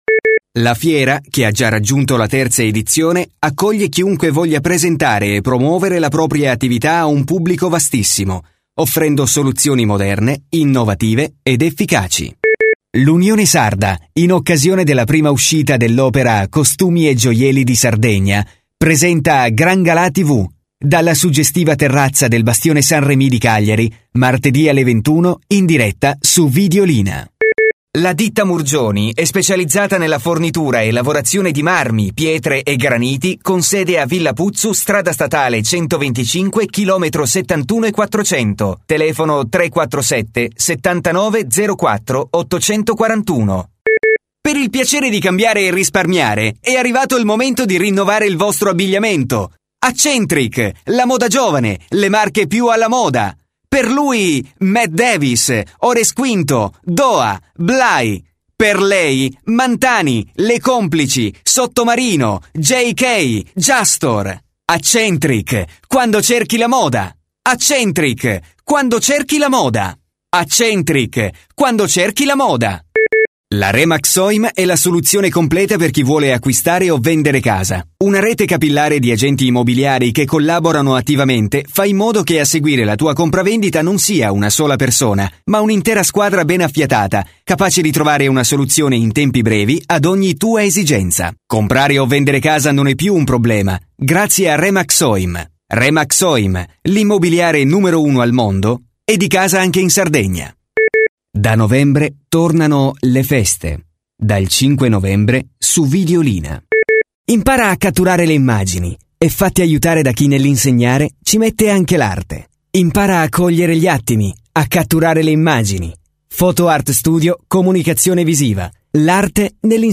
Voce giovane, adatta a comunicati dinamici, che richiedono un forte impatto energetico oppure per comunicati capaci di attirare l'attenzione con un messaggio chiaro, profondo e convincente.
Sprechprobe: Werbung (Muttersprache):
A new and fresh voice for your commercial, promos and any more!